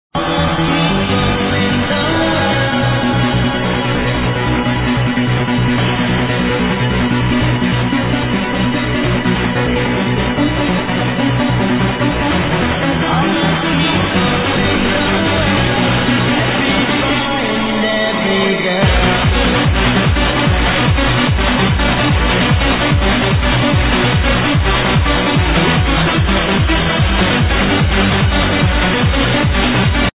HardTrance Track ID